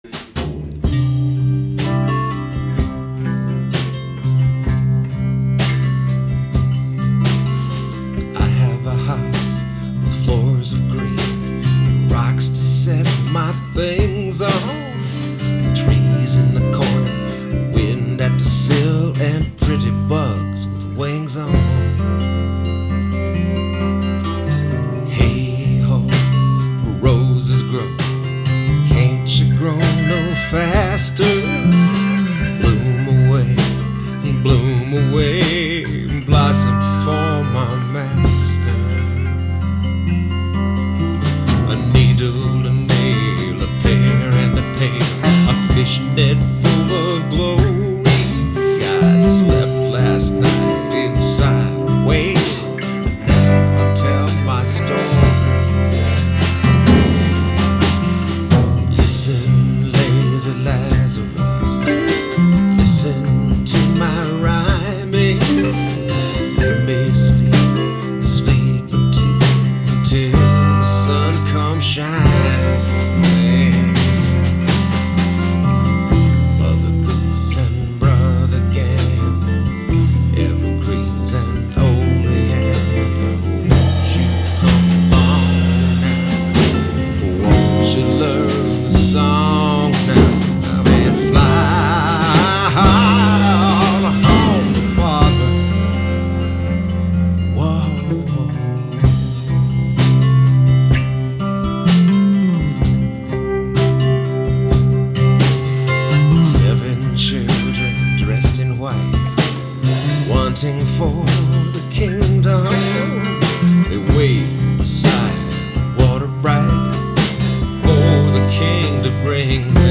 guitar
organ
bass
drums
guitar and vocals. These are all rehearsal recordings